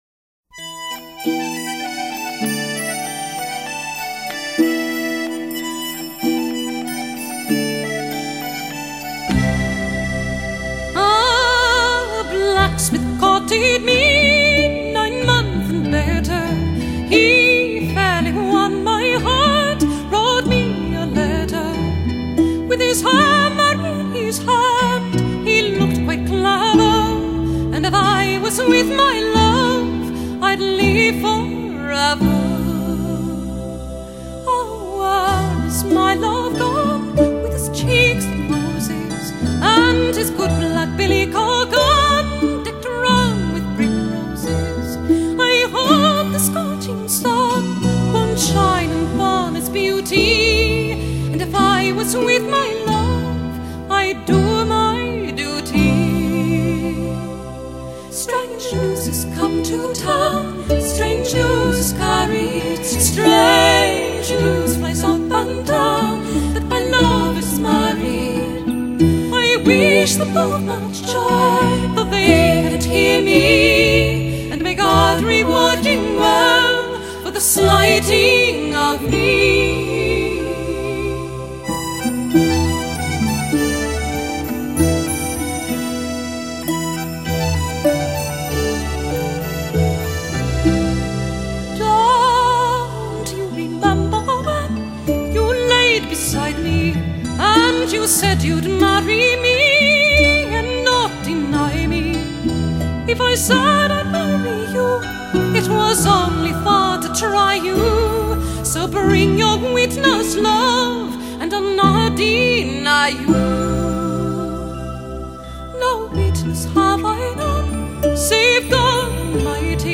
音乐类别：爵士人声
无论全碟音乐的编排，环境氛围的烘托，小鸟等自然声音的渲染，更有和男子的合唱，
一切的一切，目的就是为了表现凯尔特音乐特有的旋律和味道。